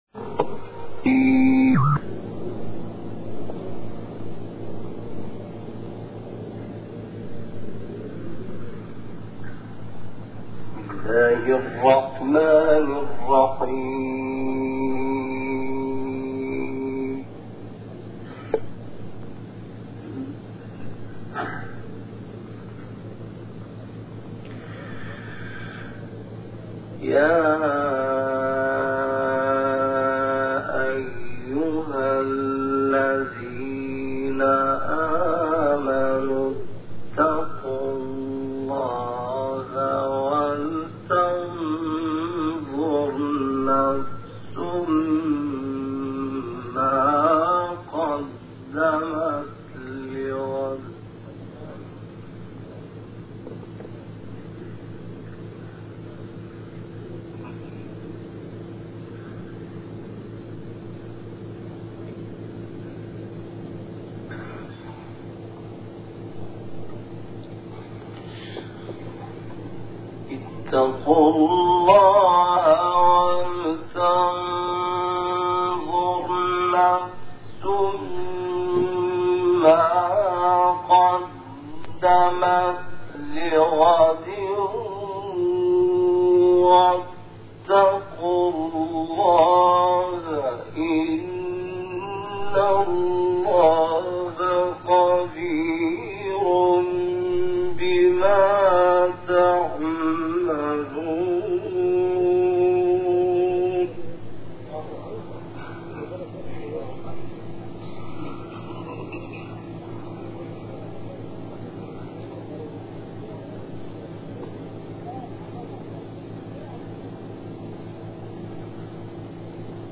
گروه فعالیت‌های قرآنی: تلاوتی کمتر شنیده شده و تصاویری از شیخ محمد اللیثی ارائه می‌شود.